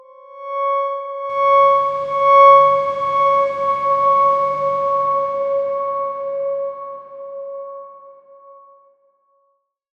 X_Darkswarm-C#5-pp.wav